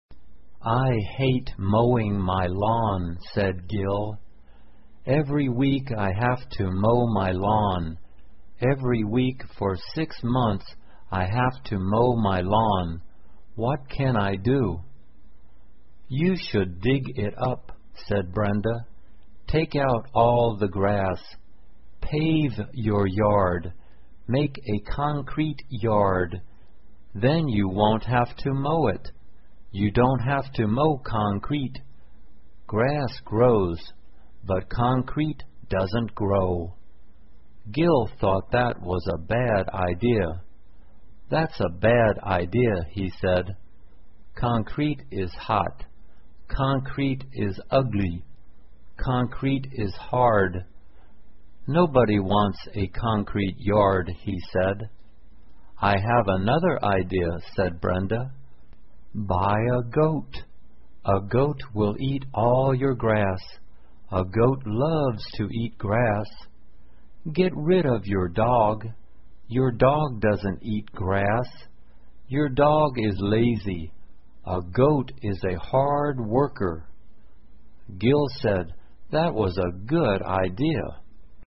慢速英语短文听力 买头羊 听力文件下载—在线英语听力室